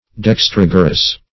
Dextrogerous \Dex*trog"er*ous\, a.
dextrogerous.mp3